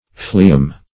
phleum - definition of phleum - synonyms, pronunciation, spelling from Free Dictionary